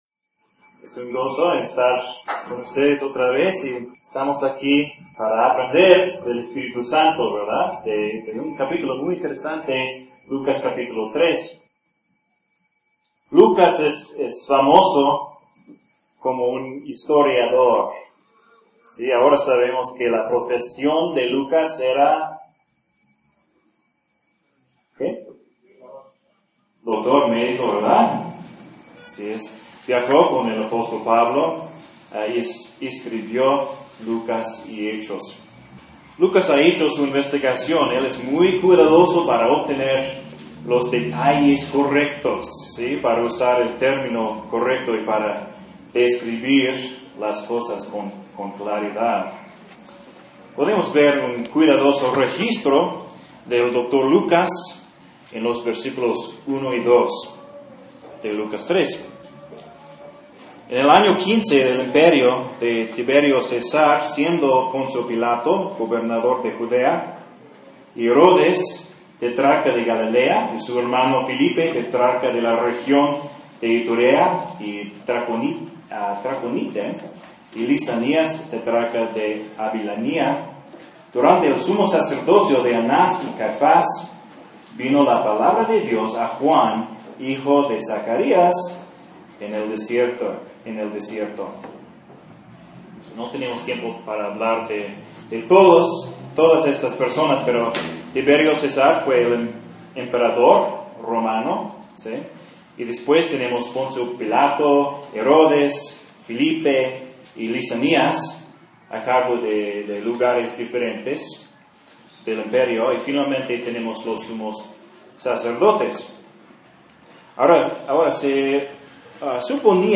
Un sermón de Lucas 3.
Lucas 3 (sermón)